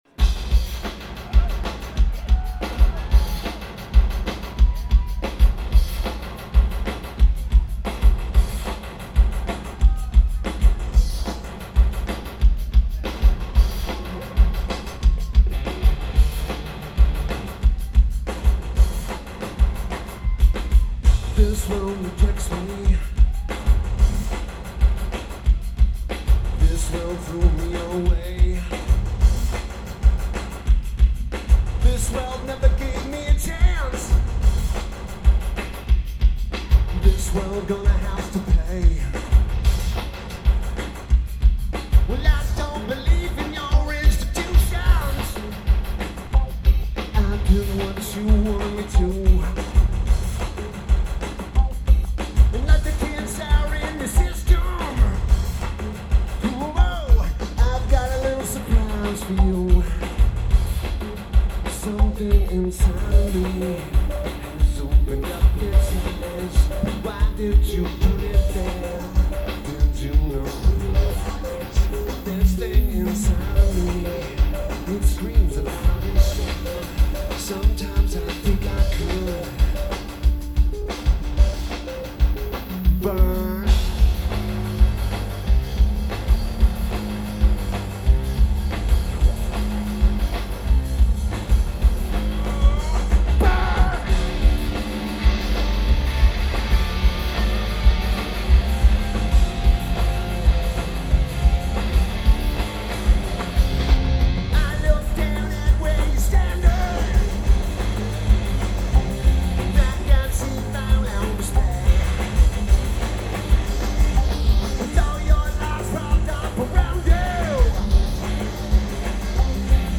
FirstBank Amphitheater